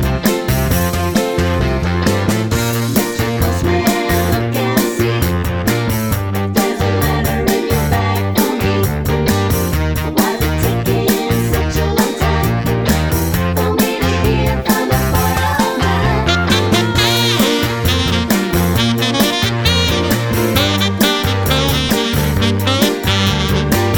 One Semitone Up Pop (1970s) 2:33 Buy £1.50